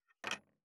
588魚切る,肉切りナイフ,
効果音厨房/台所/レストラン/kitchen食器食材